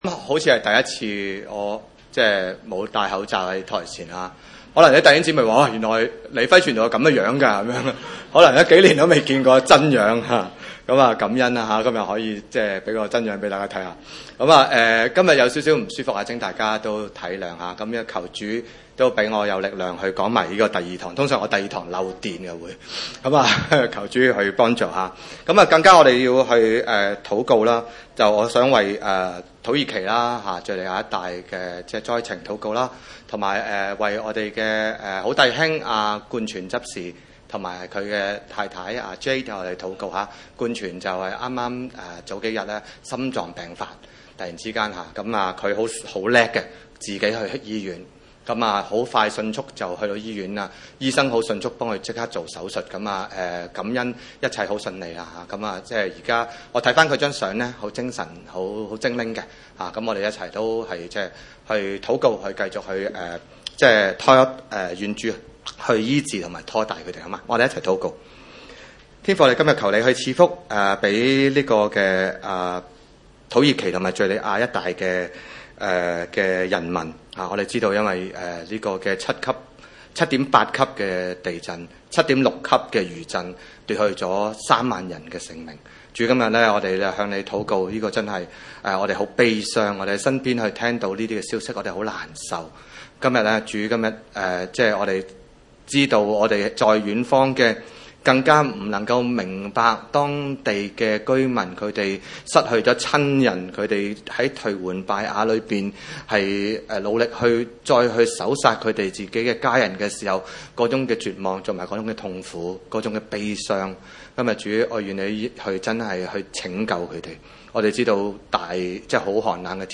經文: 約翰福音 10：1-18 崇拜類別: 主日午堂崇拜 1 我 實 實 在 在 的 告 訴 你 們 ， 人 進 羊 圈 ， 不 從 門 進 去 ， 倒 從 別 處 爬 進 去 ， 那 人 就 是 賊 ， 就 是 強 盜 。